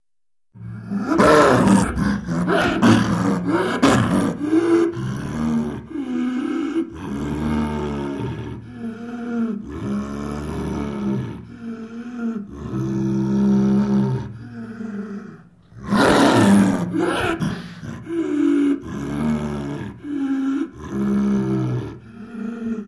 LeopardSnarlWheeze AT012301
Leopard Snarl With Wheeze Inhales. Ferocious. Close Perspective.